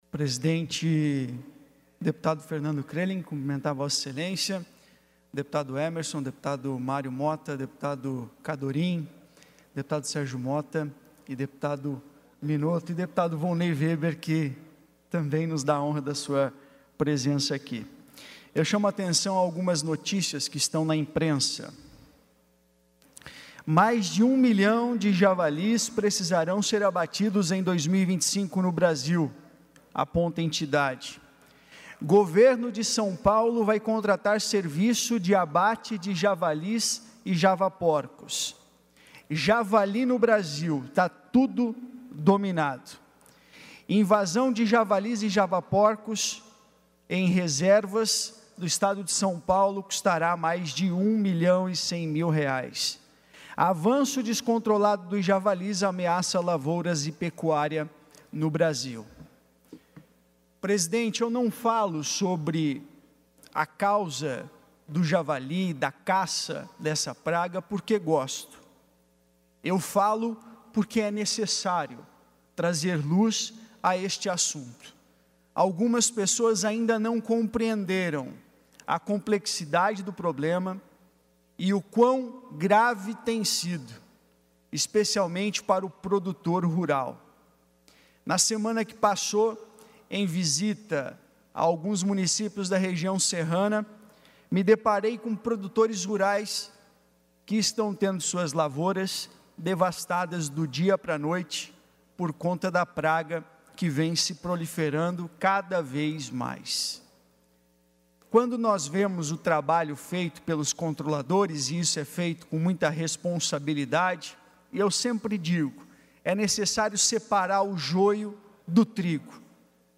Pronunciamentos da sessão ordinária desta quinta-feira (13)